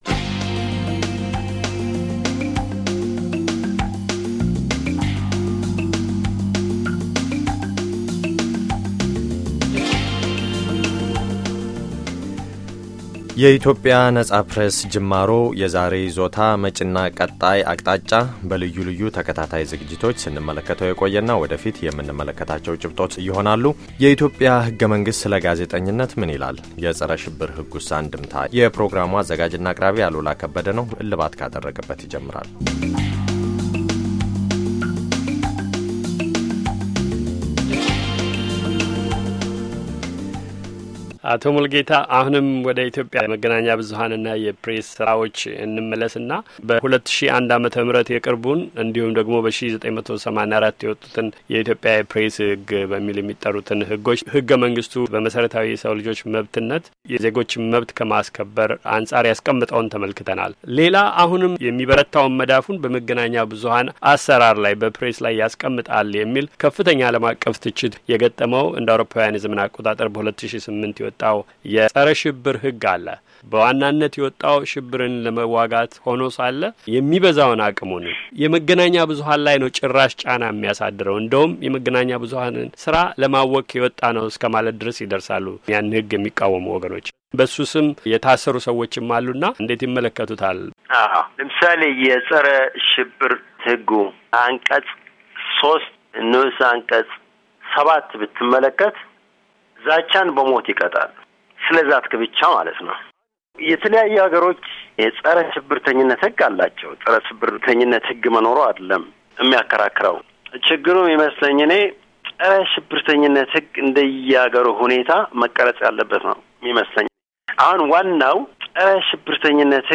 Embed share የውይይቱን ሁለተኛ ክፍል ከዚህ ያድምጡ፤ by የአሜሪካ ድምፅ Embed share The code has been copied to your clipboard.